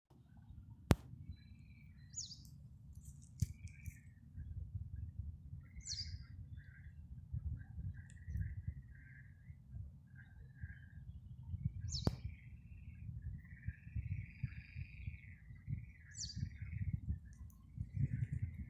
Baltā cielava, Motacilla alba
Ziņotāja saglabāts vietas nosaukumsBauskas nov Vecumnieku pag.
StatussDzied ligzdošanai piemērotā biotopā (D)